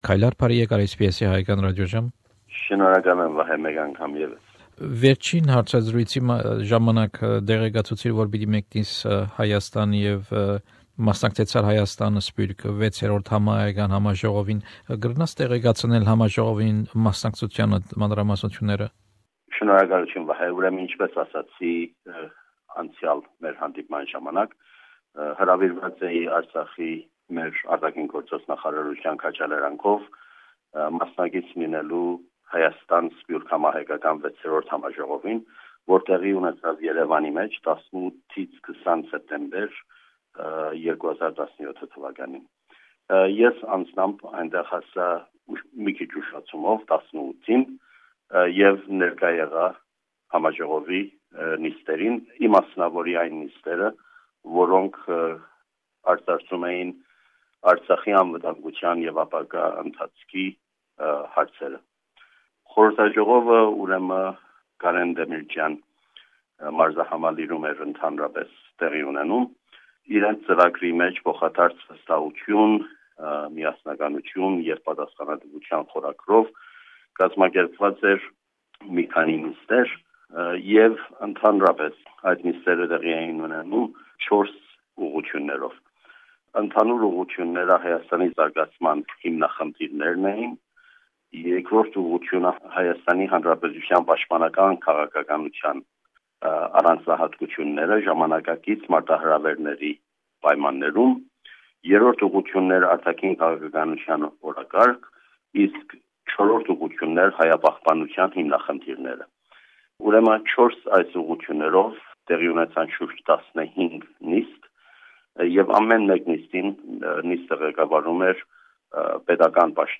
An interview with Mr Kaylar Michaelian, the Permanent Representative of Artsakh Republic to Australia, about 6th Armenia-Diaspora pan-Armenian Conference